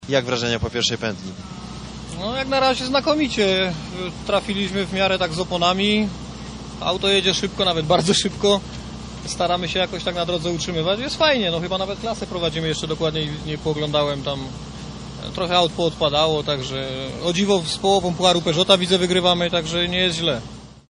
wypowiedź - 33 Rajd Elmot - serwis 1